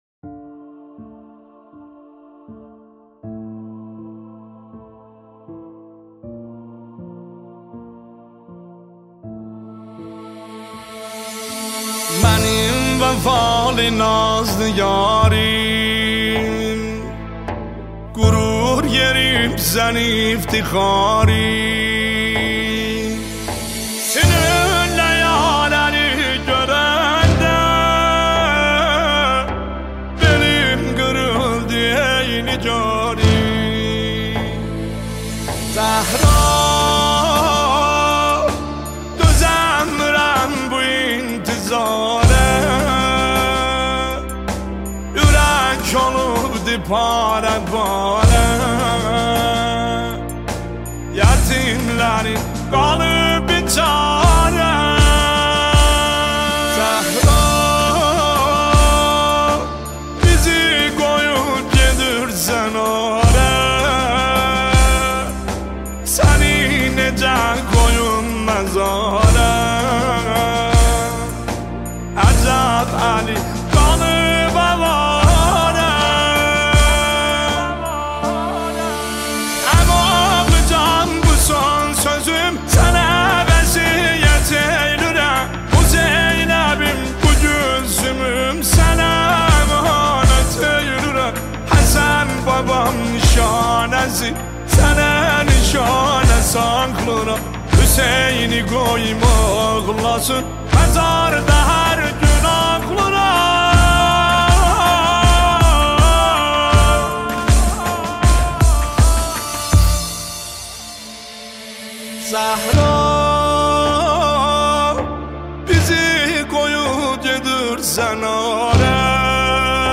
مداحی ترکی